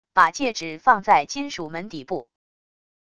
把戒指放在金属门底部wav音频